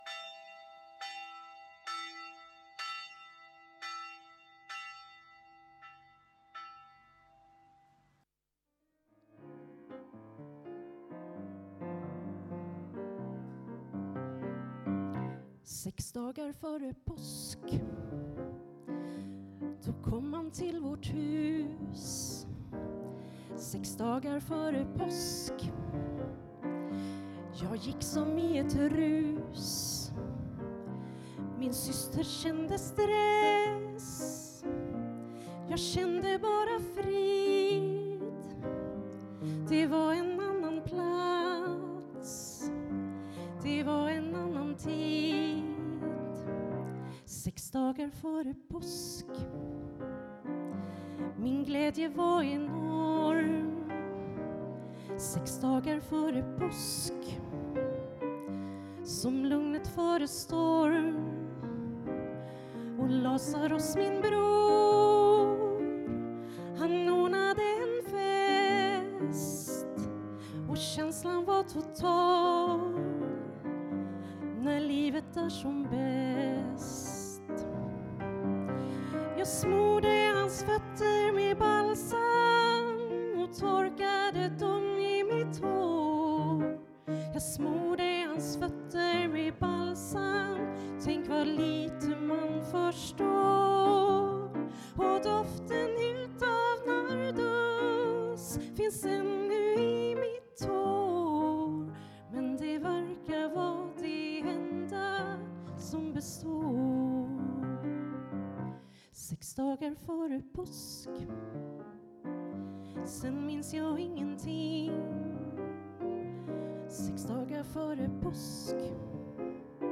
Detta avsnitt kommer från palmsöndagens gudstjänst i Älvsjökyrkan.